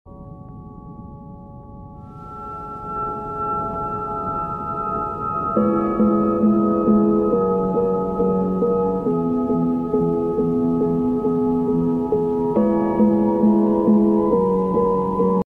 Its hard to beat evenings with cows grazing summer grass.